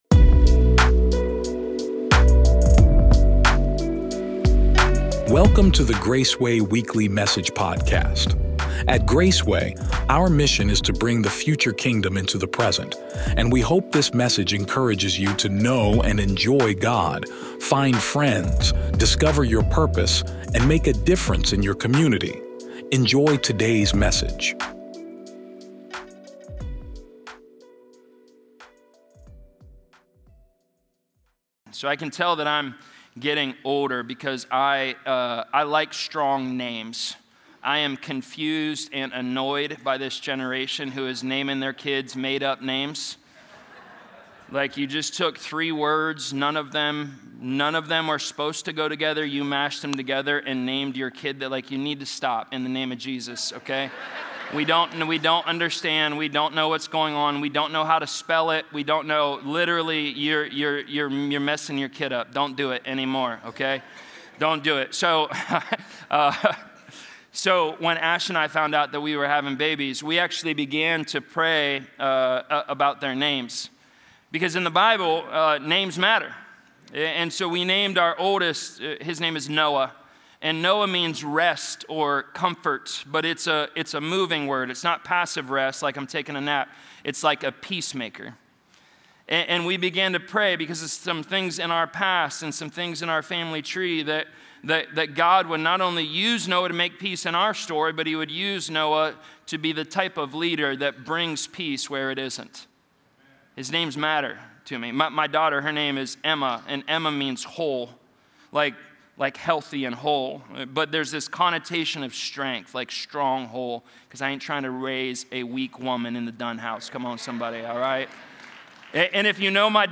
As Peter and John heal a man who had been lame from birth, we see that this miracle was not accomplished by human ability, but through faith in Jesus’ name. This sermon unpacks what it means to live under Christ’s delegated authority, empowered by the Holy Spirit, and aligned with the way of Jesus. Discover how calling on the name of Jesus brings healing, restoration, boldness, and transformation.